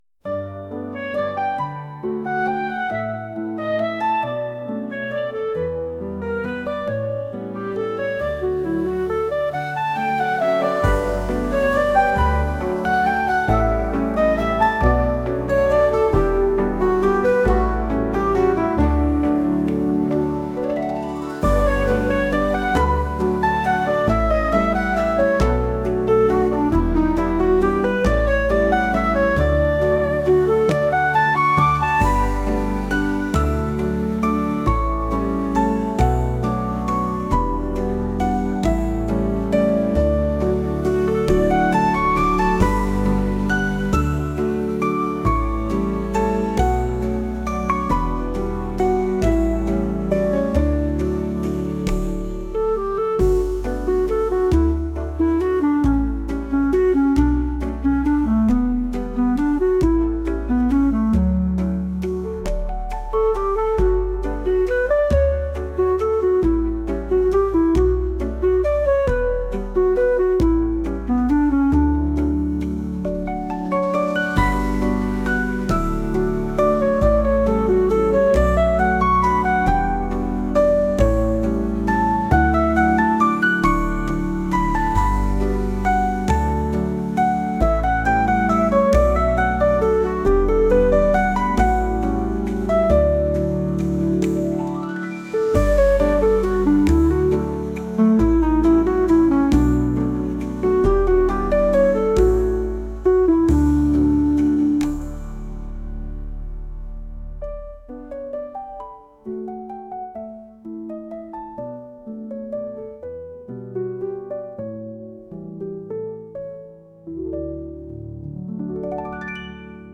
クラリネットとピアノを合わせたのんびり曲です。